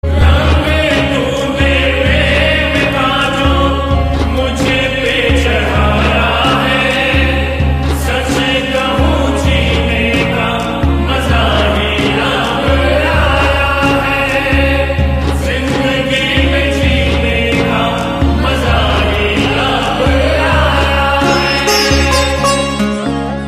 BHAKTI DEVOTIONAL BHAJAN